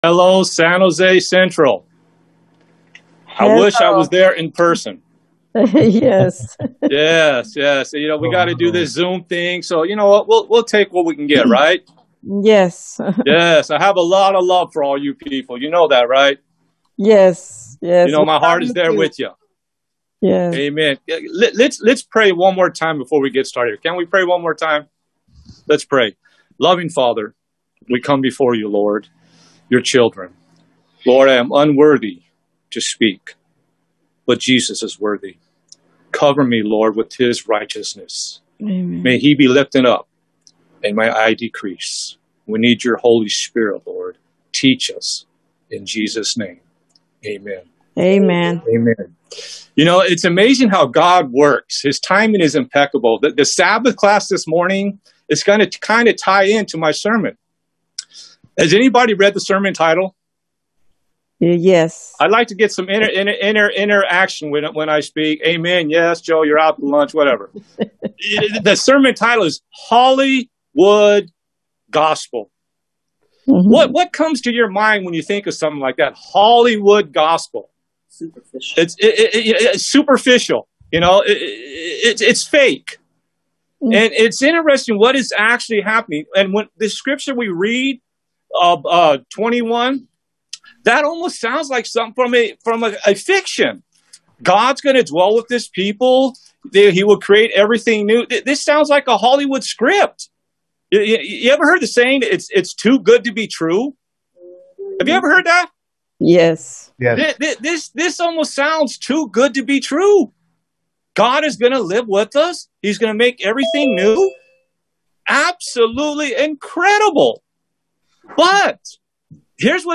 Seventh-day Adventist Church